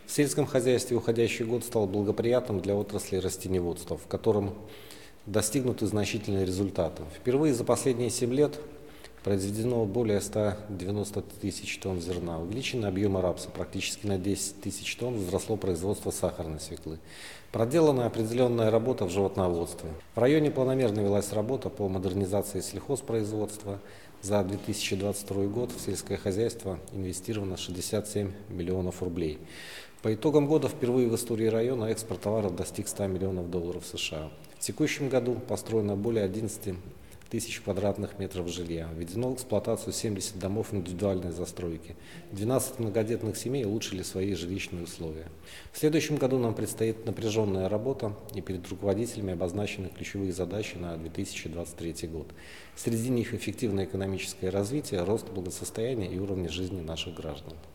Состоялось совместное заседание Барновичского райисполкома и районного Совета депутатов
Всё это результат совместных усилий власти, предприятий и организаций различных форм собственности, отметил председатель райисполкома Сергей Карпенко.